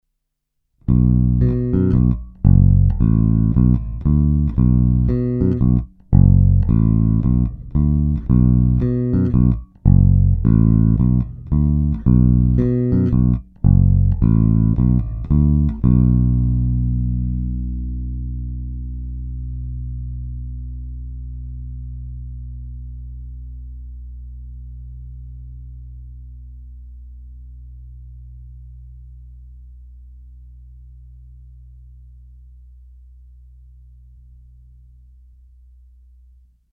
Struna H má velmi slušný přednes a artikulaci.
Ukázky jsou nahrány rovnou do zvukové karty a jen normalizovány.
Snímač u krku